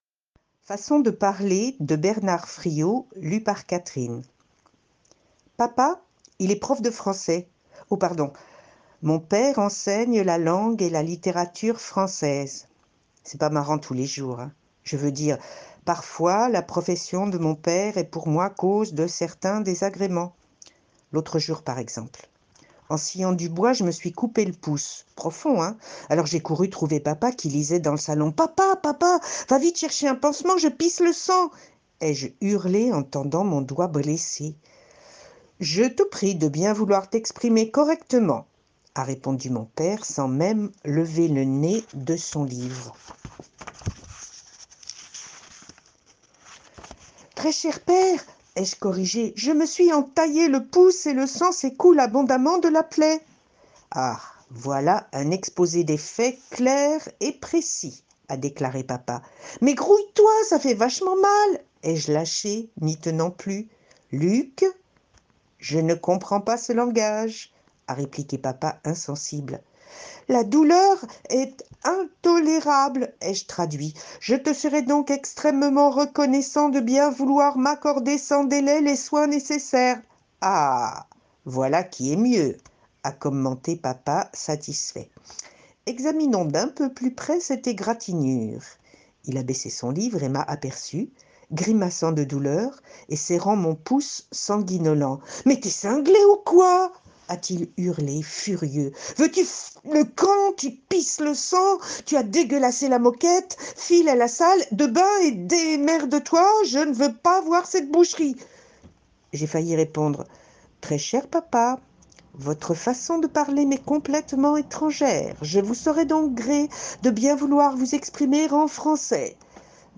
Des histoires courtes à écouter
la lecture à voix haute a de nombreux bienfaits que ce soit pour la personne qui lit que pour la ou les personnes qui écoutent.